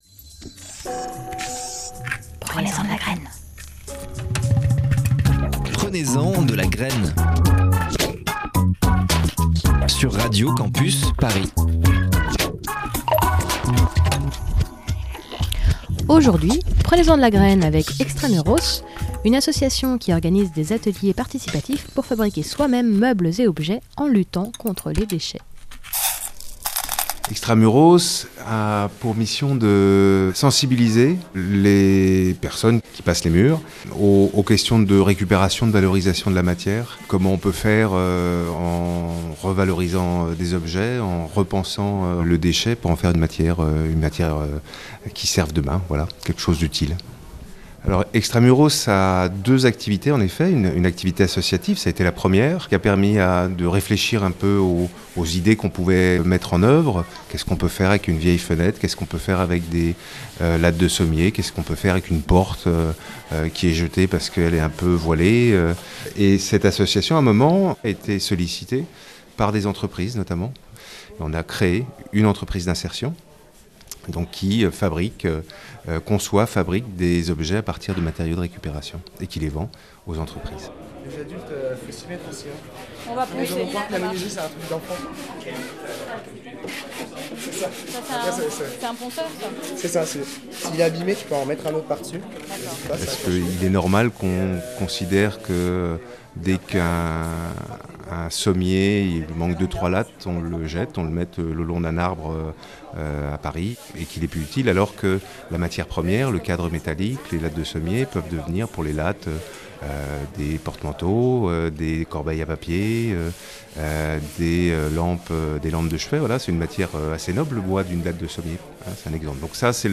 Cette semaine, petite visite lors de l'inauguration d'une série d'ateliers dont le but est de fabriquer ou donner une seconde vie à ses meubles !